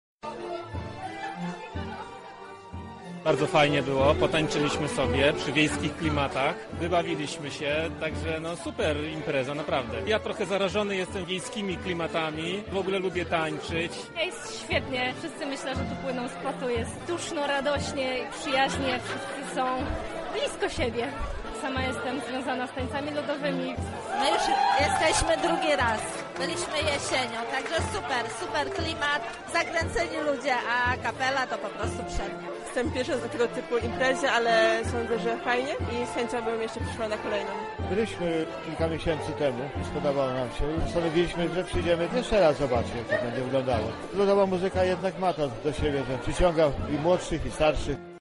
Lublinianie tłocznie bawili się przy dźwiękach muzyki tradycyjnej
Koszule korale i folkowe rytmy – tak wyglądał ten wieczór.
Tłumy i pełny parkiet a tam królowały polki, oberki, majdaniaki i walce.
potancowka.mp3